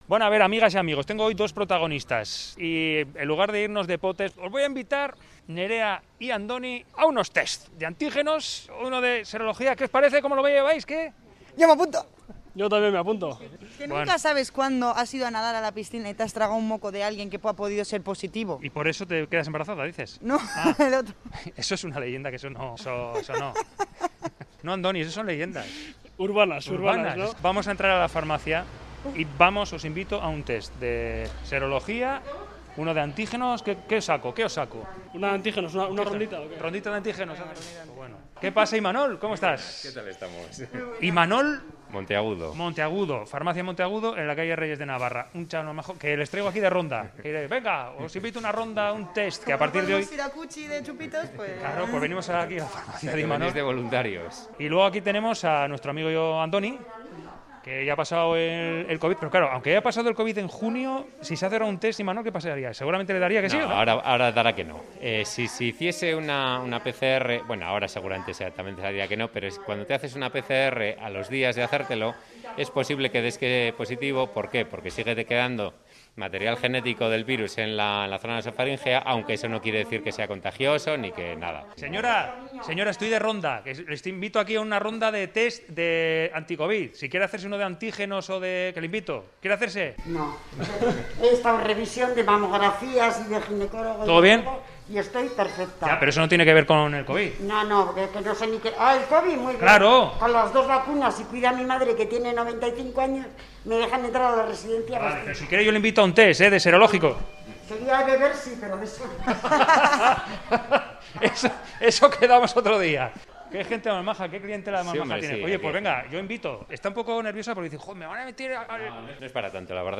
Audio: Reportaje: Haciéndonos el test de antígenos de las farmacias